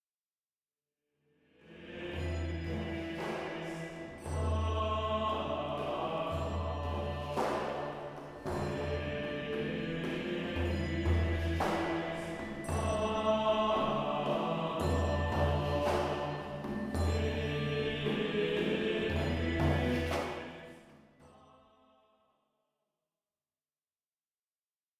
Plain-chant et polyphonies du 14e siècle